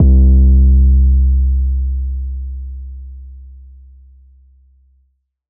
808_Oneshot_Cyber_C
808_Oneshot_Cyber_C.wav